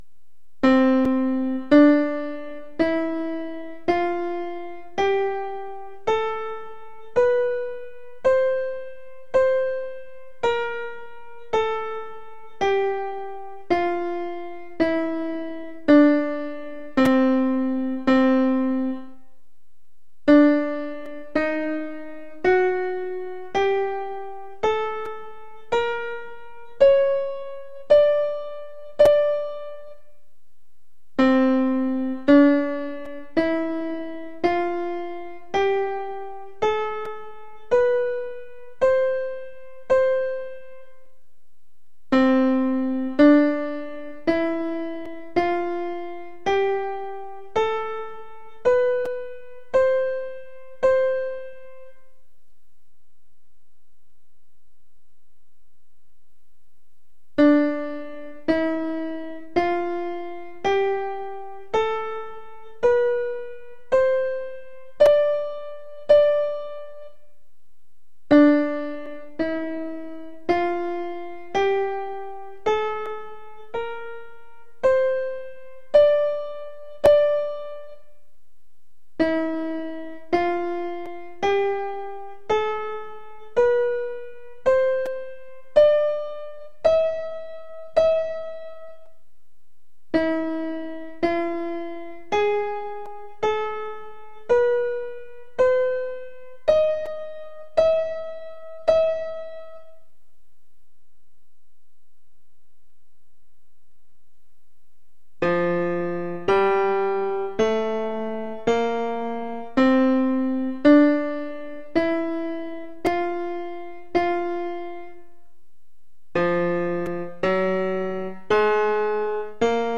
Of all the church modes, the most exotic-sounding ones come from the Byzantine Church tradition — along with several very familiar-sounding scales!
53-figure-7-5-Byzantine-Scales.mp3